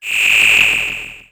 Hum40.wav